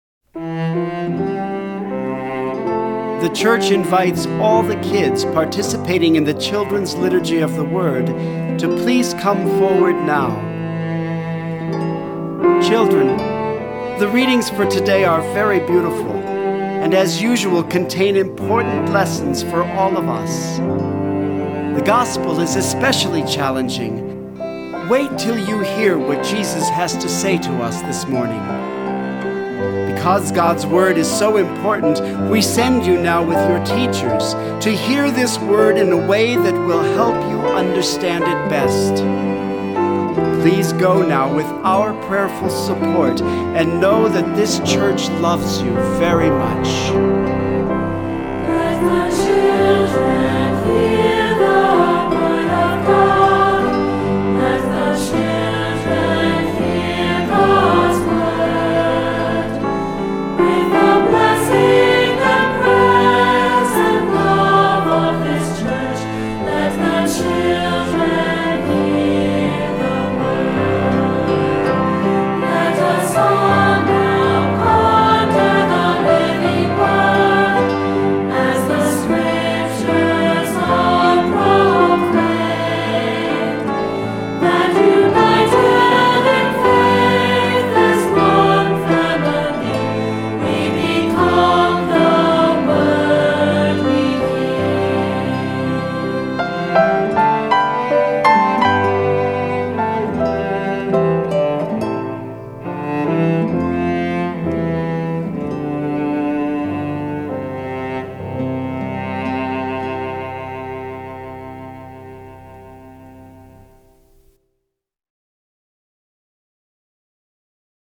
Voicing: Unison Choir, Cantor